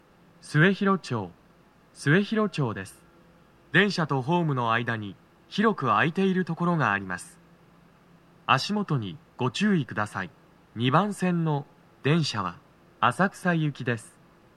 スピーカー種類 TOA天井型
🎵到着放送
2番線には足元注意喚起放送が付帯されており、粘りが必要です。
男声